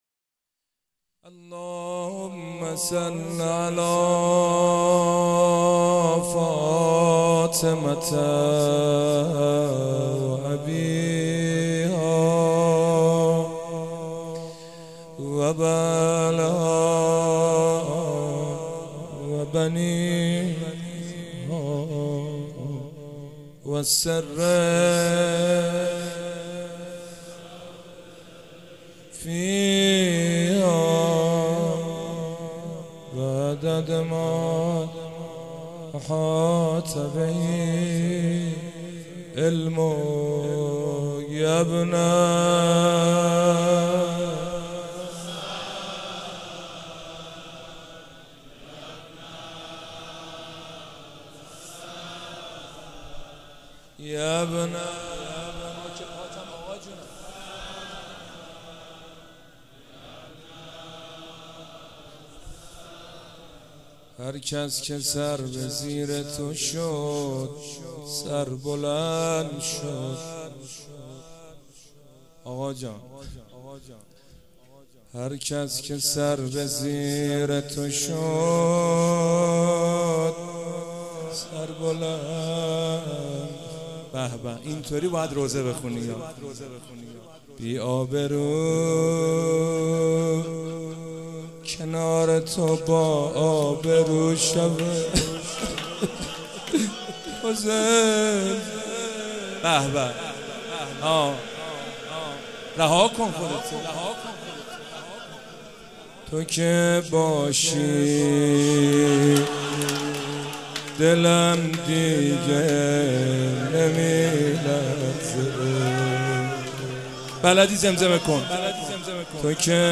روضه امام محمد باقر